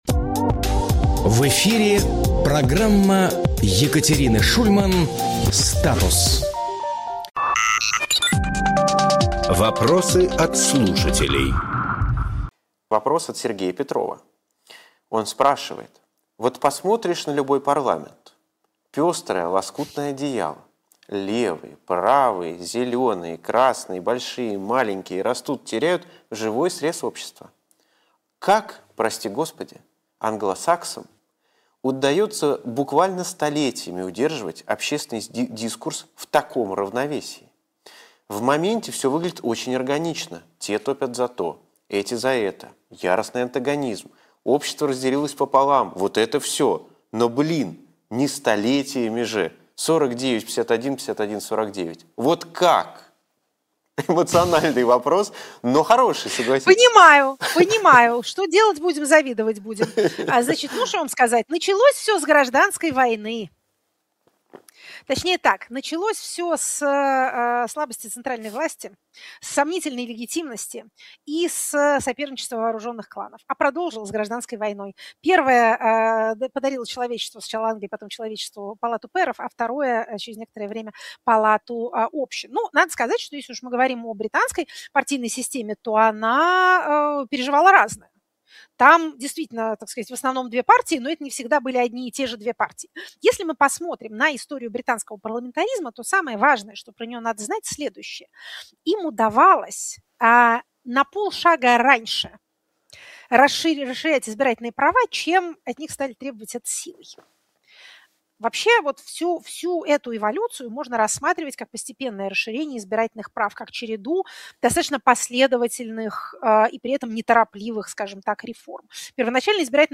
Фрагмент эфира от 20.01.2026